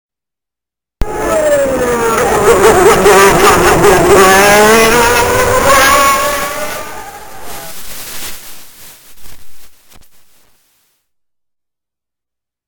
IWAYA ENGINE SOUND COLLECTION